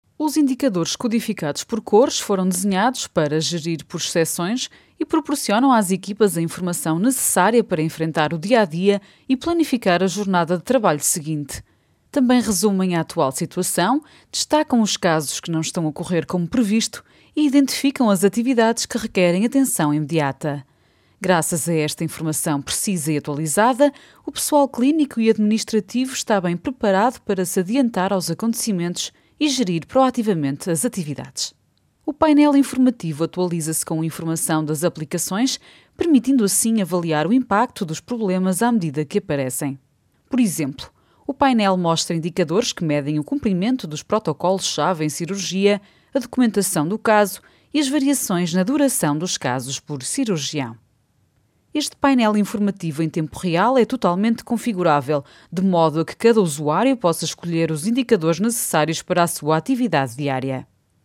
Portuguese native speaker and voice over talent.
Sprechprobe: eLearning (Muttersprache):